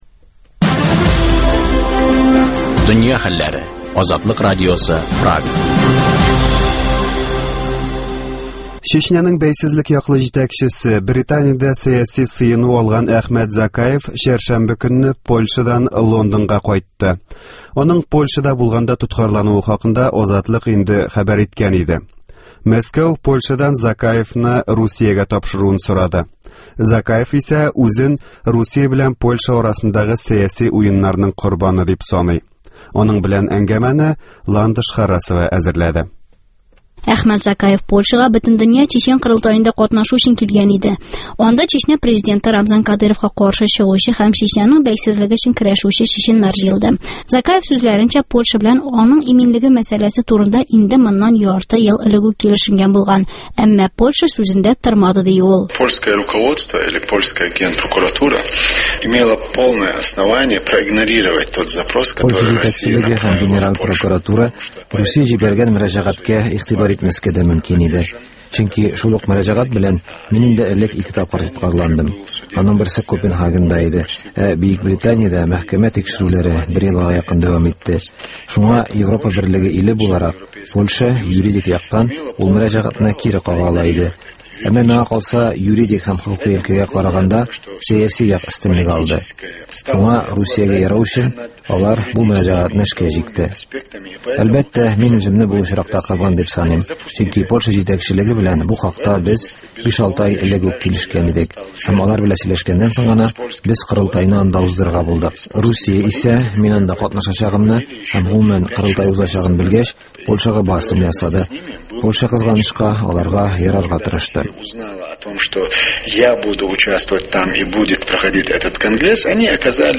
Әхмәт Закаев “Азатлык” радиосына әңгәмә бирде